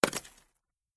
Звук падения нунчаков на землю